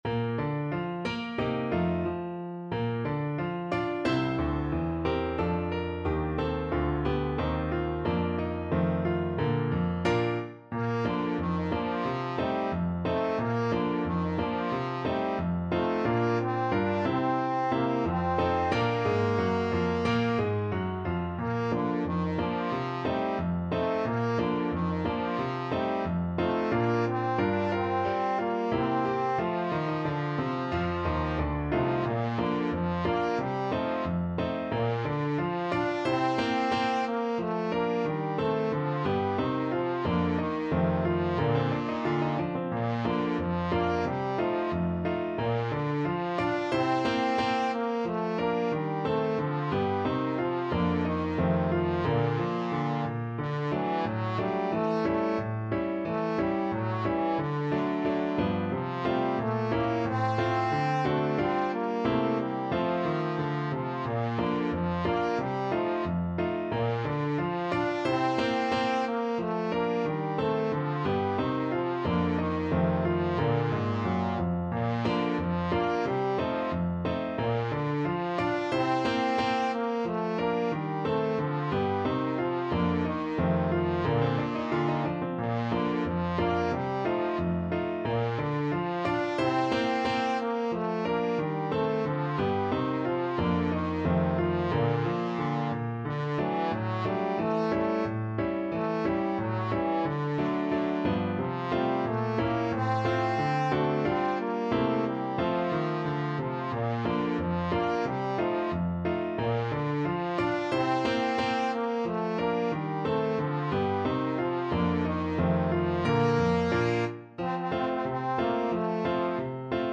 Trombone version
2/2 (View more 2/2 Music)
=90 Fast and cheerful
Pop (View more Pop Trombone Music)